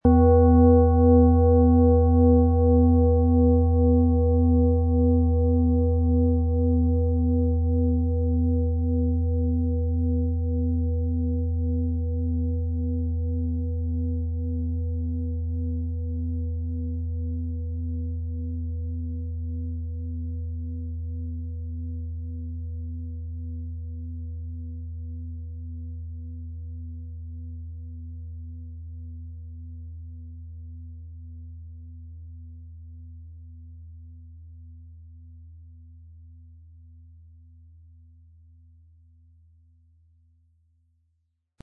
XXL-Planeten-Fußreflexzonen-Klangschale - Leichtigkeit und Geborgenheit mit Delfin, bis Schuhgröße 39, ca.8,6 kg und Ø 46 cm, mit Klöppel
Planetenton 1
Von Hand getriebene Klangschale mit dem Planetenklang Delfin aus einer kleinen traditionellen Manufaktur.
• Tiefster Ton: Hopi-Herzton
Im Audio-Player - Jetzt reinhören hören Sie genau den Original-Ton der angebotenen Schale.